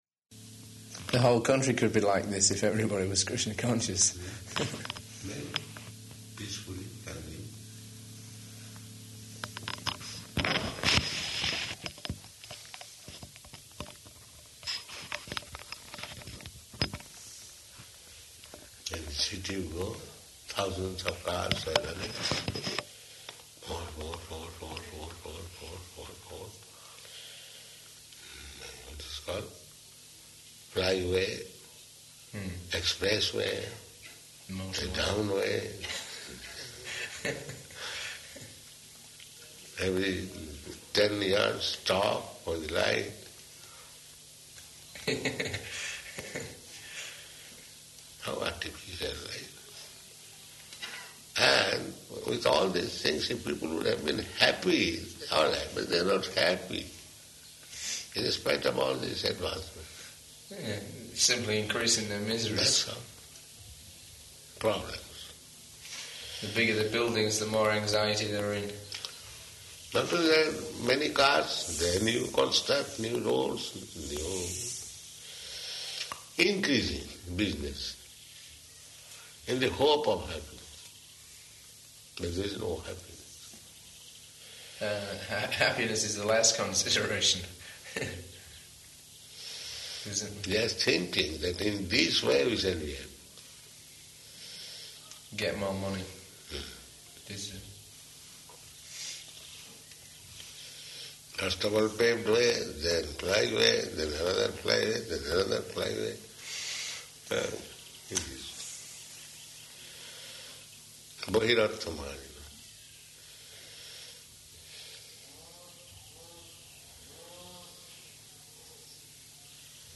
Room Conversation
-- Type: Conversation Dated: August 2nd 1976 Location: New Māyāpur Audio file